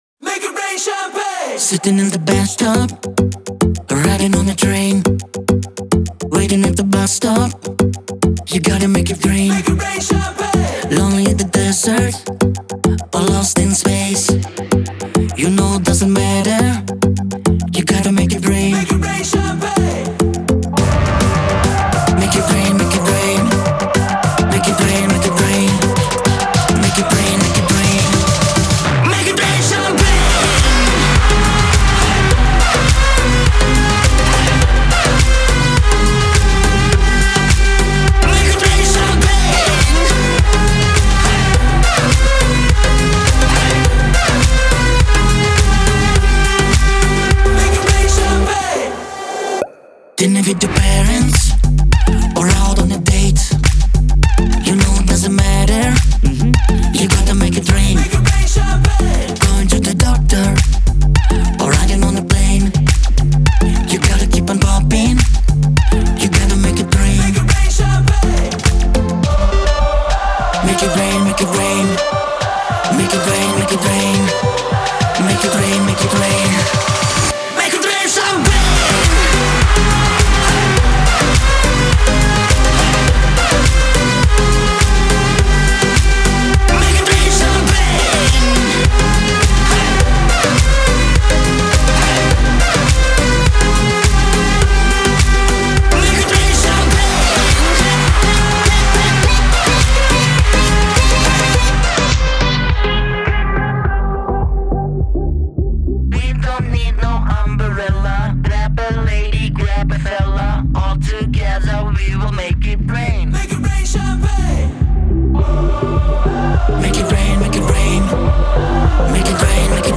это зажигательная поп-песня